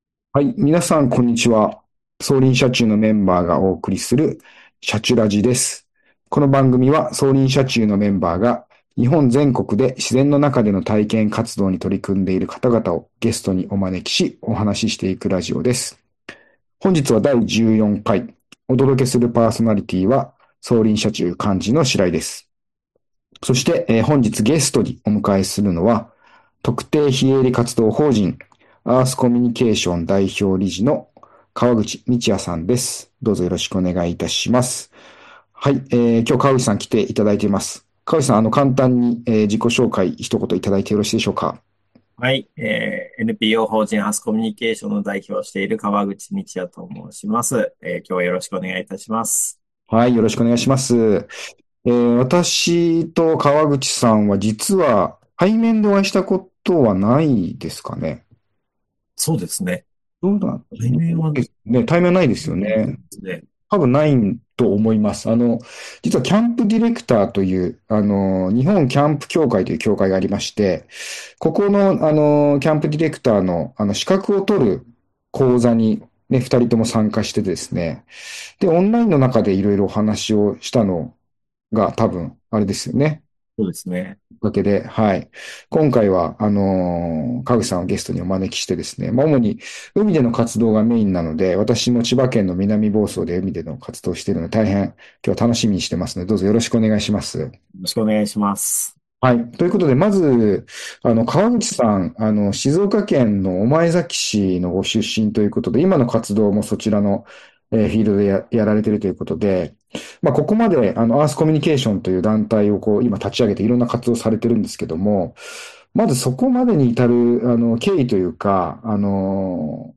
「シャチュラジ」この番組は、走林社中のメンバーが日本全国で自然の中での体験活動に取り組んでいる方々をゲストにお招きし、お話ししていくラジオです。